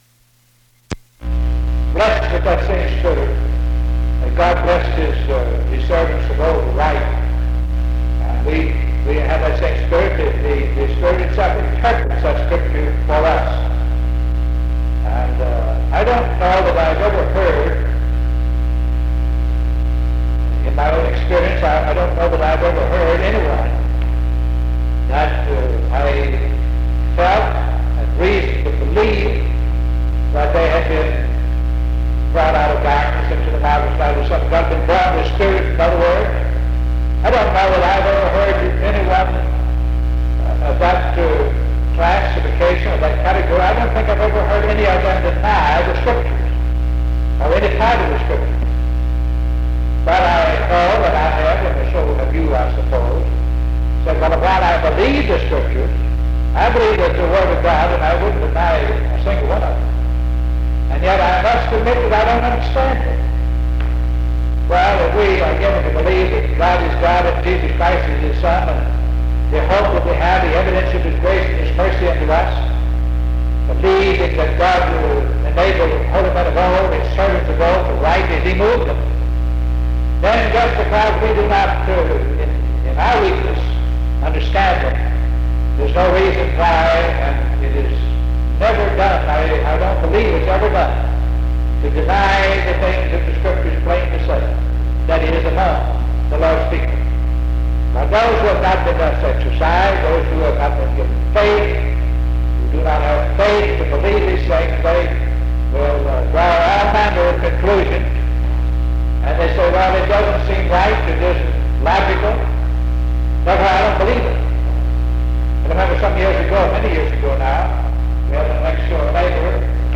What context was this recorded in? Galatians 3:23-29 (Poor quality recording)